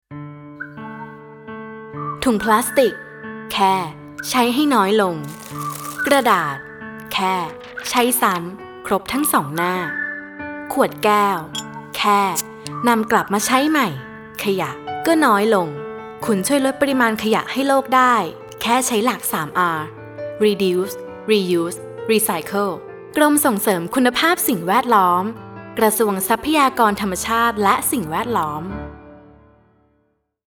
ชื่อสื่อ : สปอตวิทยุ รณรงค์ลดขยะโดย 3R
SPOT_รณรงค์ลดขยะโดย3R.mp3